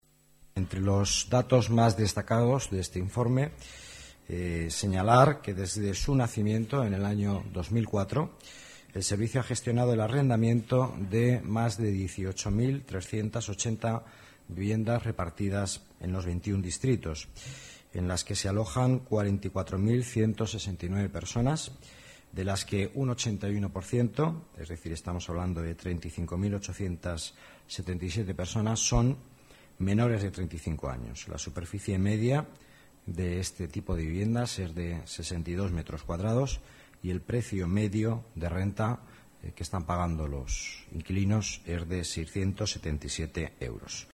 Nueva ventana:Declaraciones del vicealcalde de Madrid, Miguel Ángel Villanueva